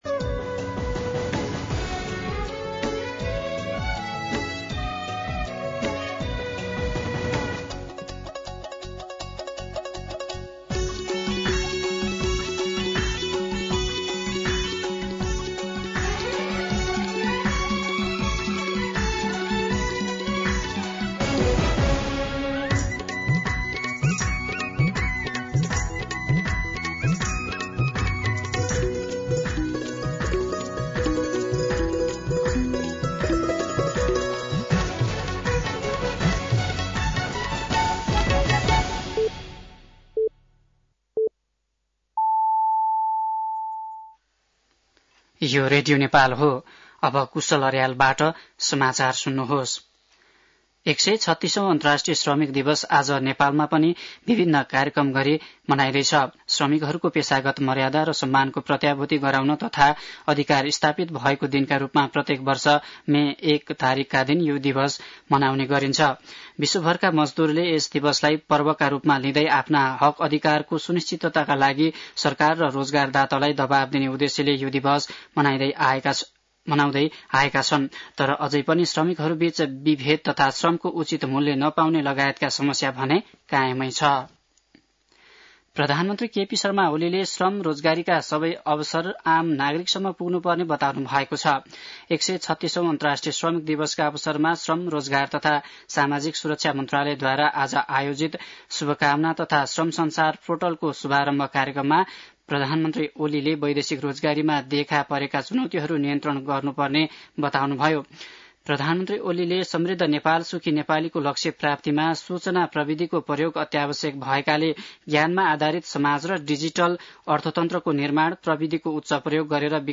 दिउँसो ४ बजेको नेपाली समाचार : १८ वैशाख , २०८२